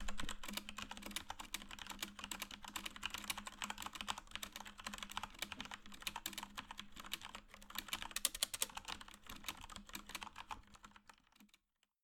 1 (1)_keyboard
computer keyboard typing sound effect free sound royalty free Memes